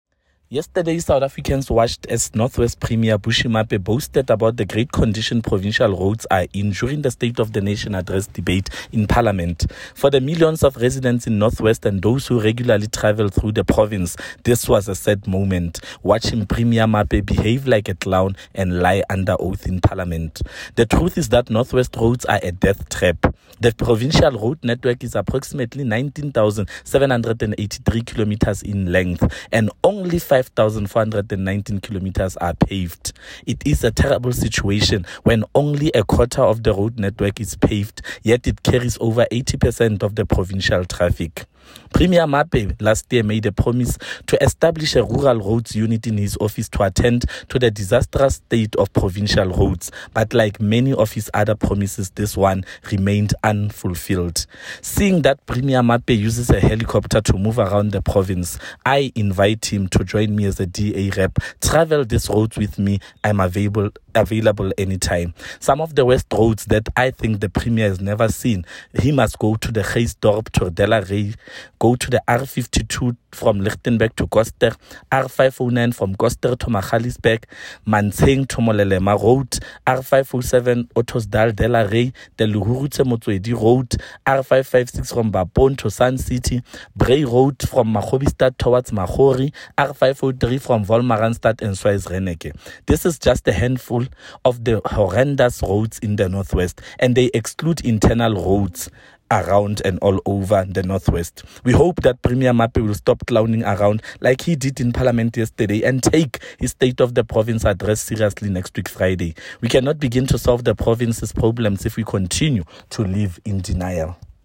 Issued by Freddy Sonakile MPL – DA Caucus Leader: North West Provincial Legislature
Note to Broadcasters: Find linked soundbites in